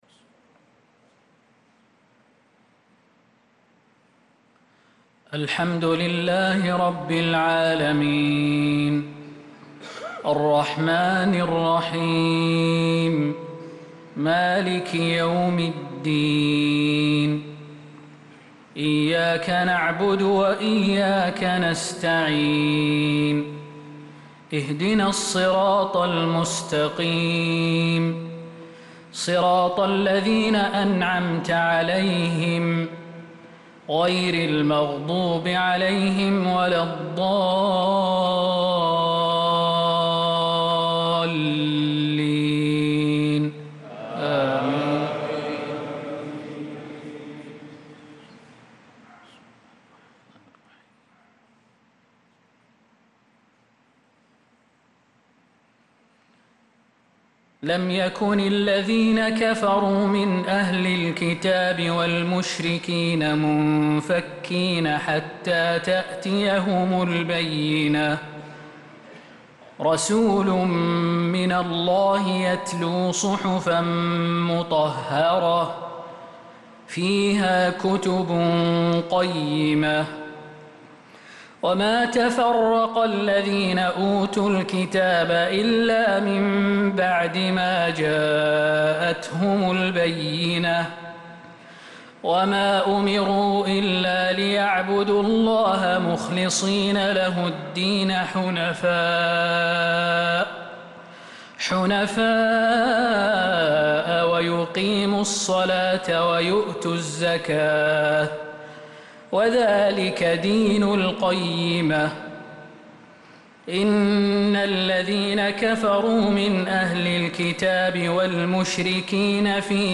صلاة العشاء للقارئ خالد المهنا 1 ذو الحجة 1445 هـ
تِلَاوَات الْحَرَمَيْن .